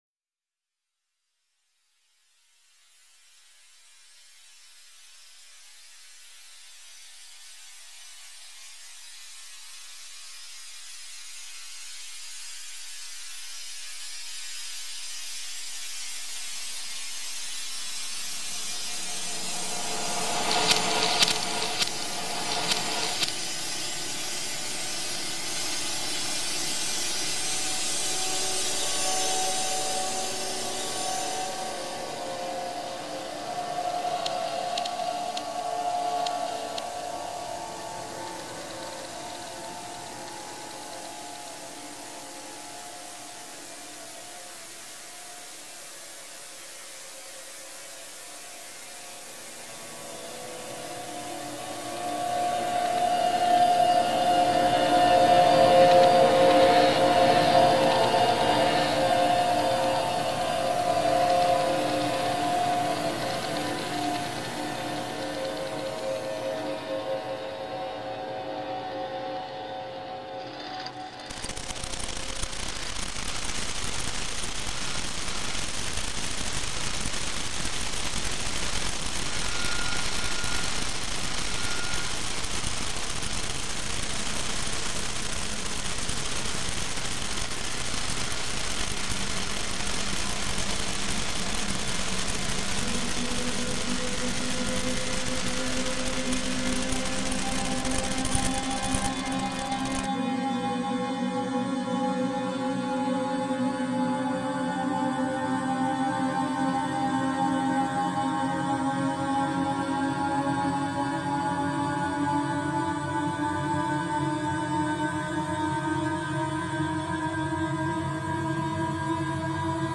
""Phoenix ou Lam(in)e(s)" is a short piece composed of small portions taken from the radiophonic original (in particular the Russian songs) and completely re-transformed to be grafted into a new sound tissue produced with a modified analogue magnetophone and demagnetized tape....
documenting and reimagining the sounds of shortwave radio